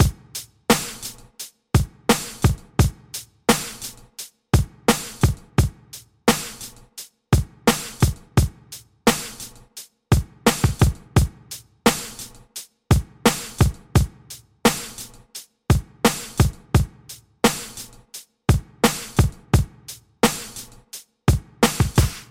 大鼓
标签： 86 bpm Hip Hop Loops Drum Loops 3.76 MB wav Key : Unknown
声道立体声